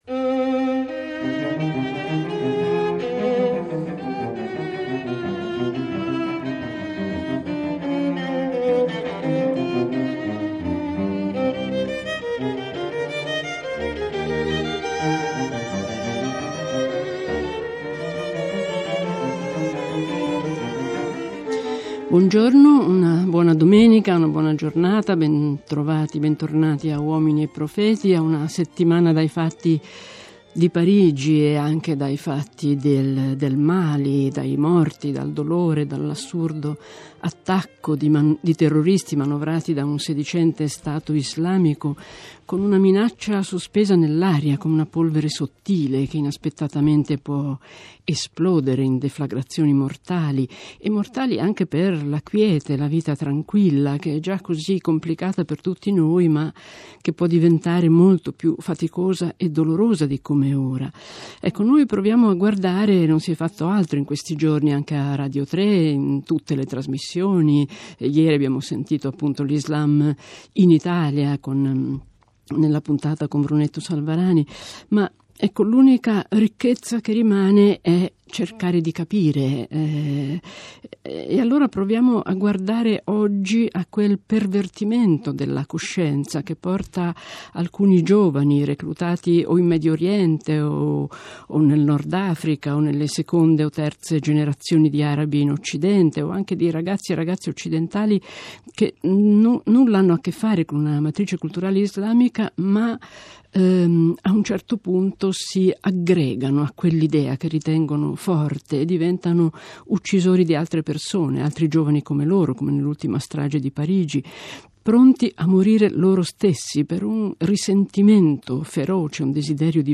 da "uomini e profeti" trasmissione di radio tre.